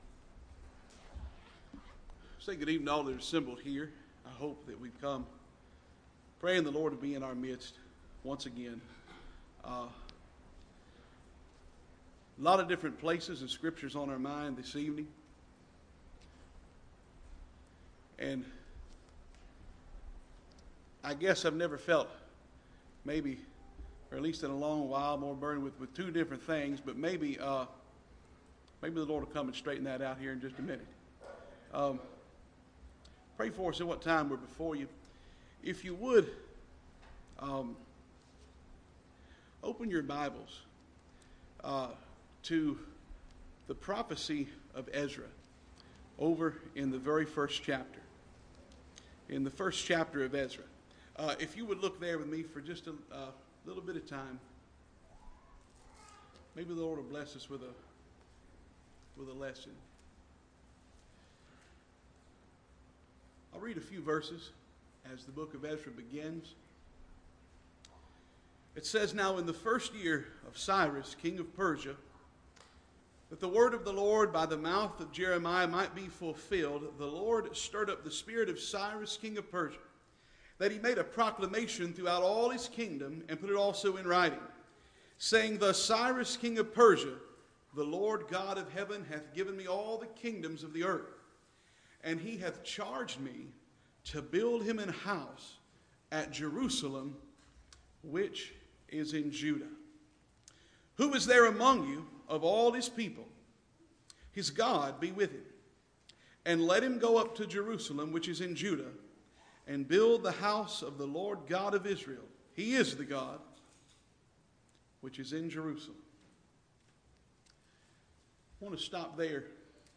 Mt Zion Association 2019, Friday Session – Rocky Mount Church